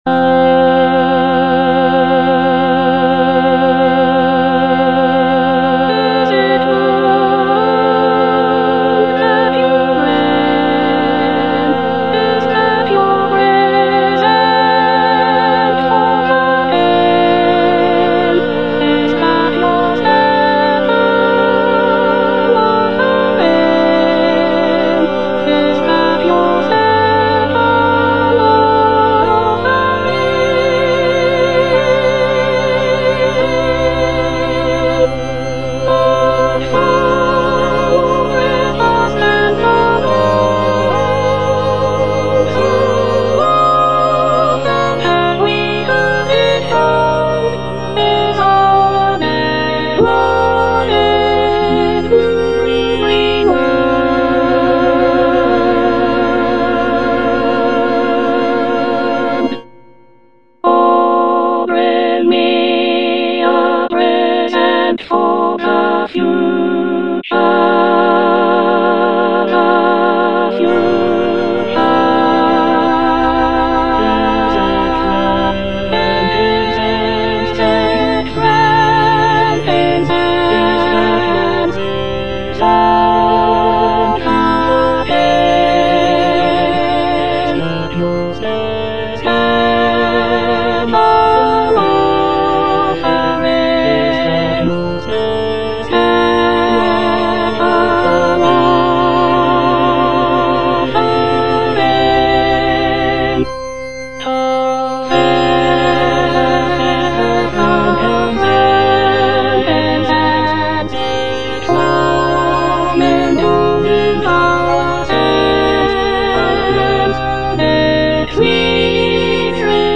Soprano I (Emphasised voice and other voices)
choral work